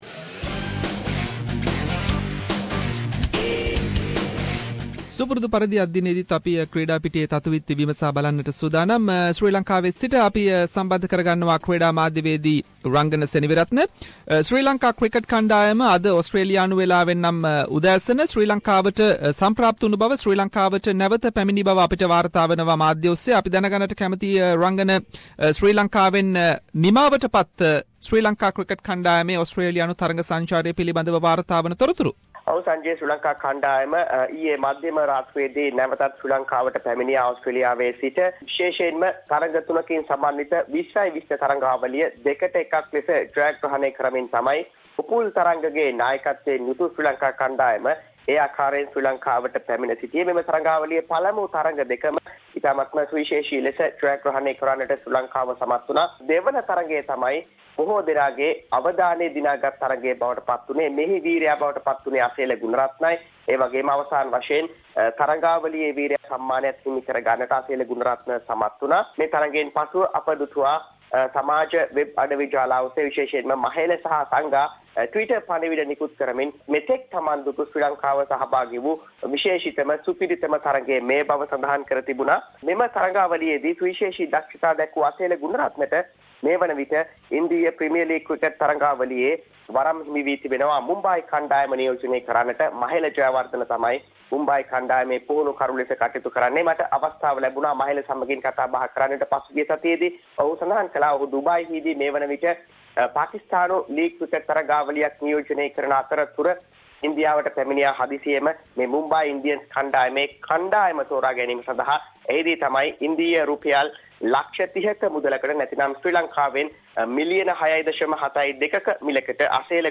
Weekly Sports wrap – Sri Lanka women’s cricket team qualify for 2017 world cup